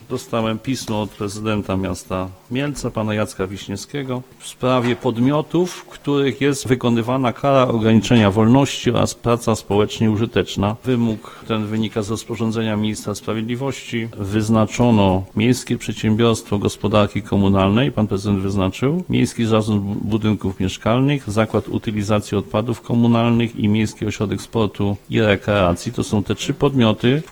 Mówi przewodniczący Rady Miasta Bogdan Bieniek: